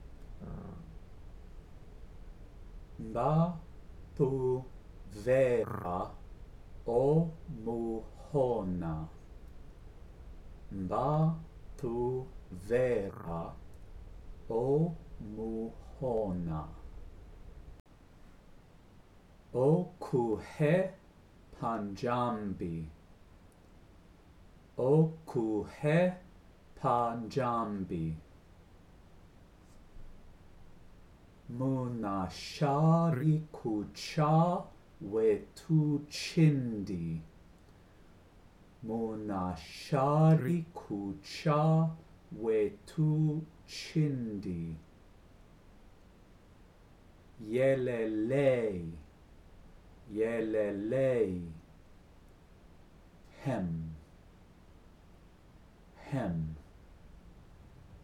Pronunciation Guide
muhona_-_pronunciation_guide.mp3